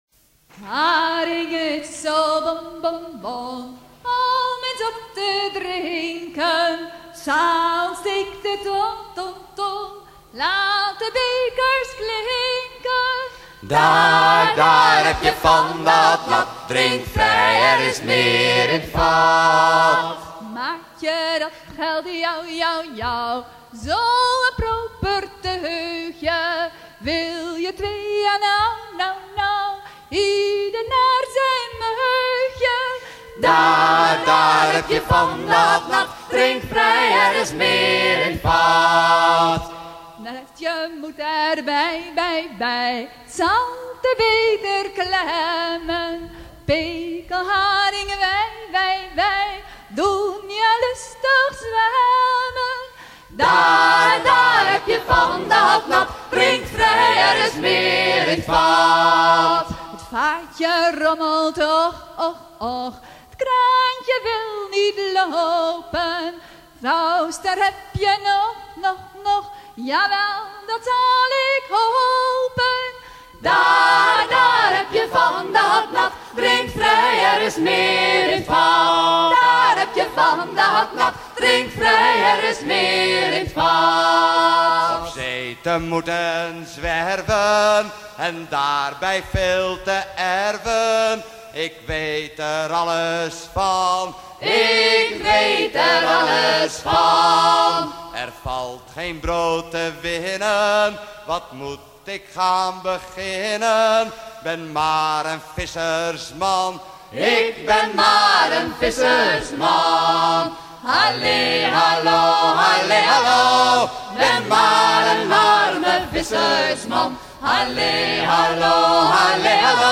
trois chants de pêcheurs des Pays-Bas
Pièce musicale éditée